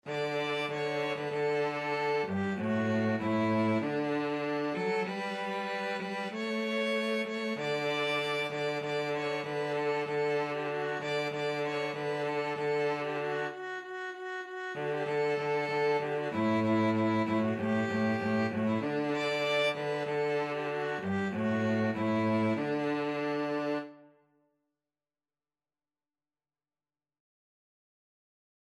Free Sheet music for Violin-Cello Duet
"Joy to the World" is a popular Christmas carol.
D major (Sounding Pitch) (View more D major Music for Violin-Cello Duet )
2/4 (View more 2/4 Music)
Traditional (View more Traditional Violin-Cello Duet Music)